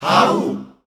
Index of /90_sSampleCDs/Voices_Of_Africa/ShortChantsShots&FX